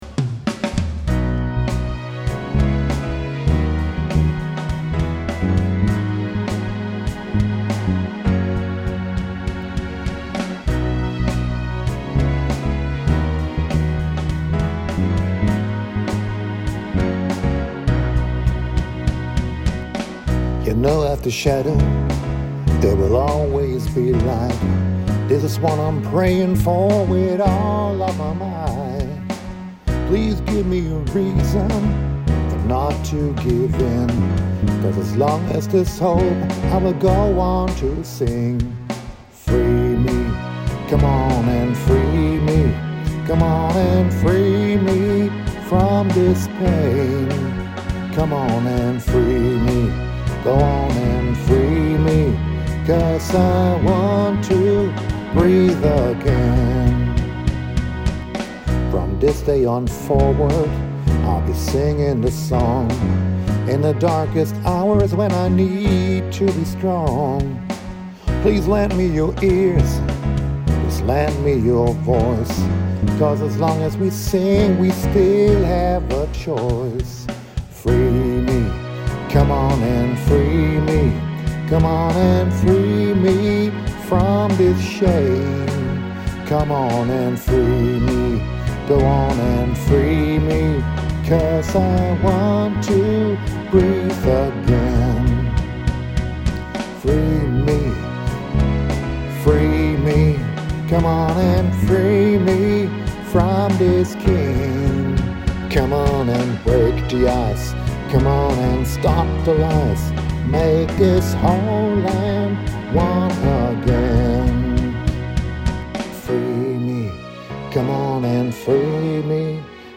Demo – Unpublished
AI remixed version of a protest song